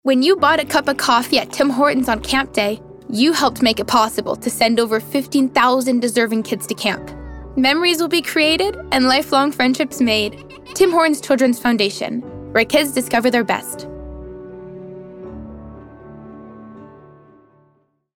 Publicité (1) - ANG